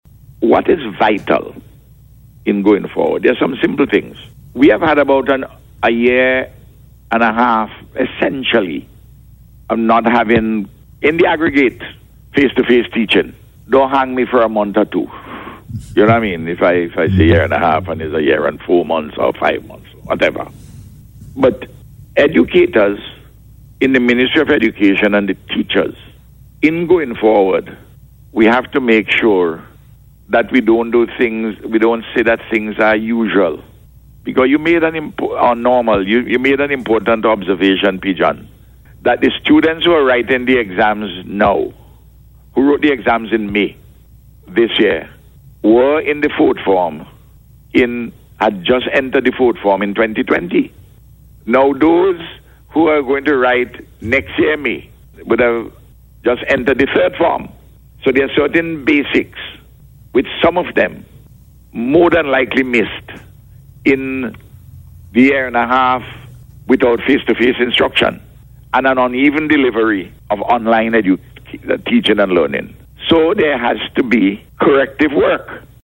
The Prime Minister discussed the strengths and weaknesses, as he analyzed the results on radio yesterday.